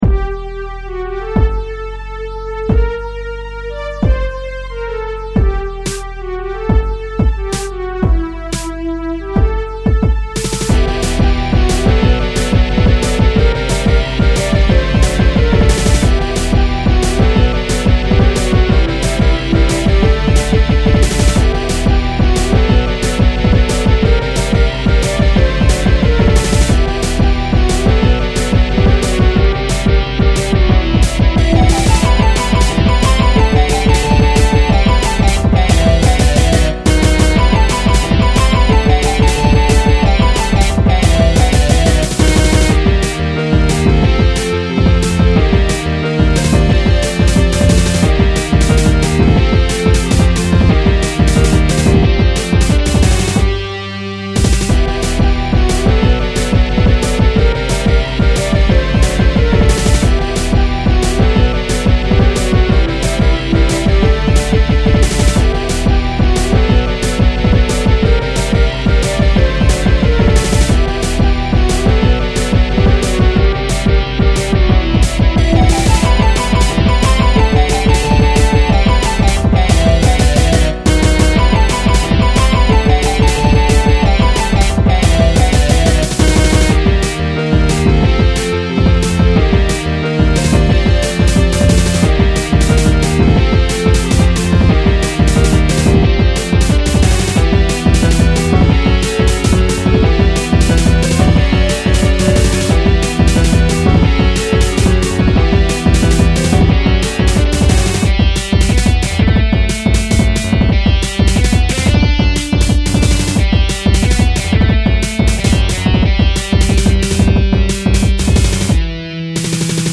ステレオ   打ち込み音重視のハードな曲です。
新入荷のドラムセットの音もさることながら、SGMとの絶妙なマッチが最高です。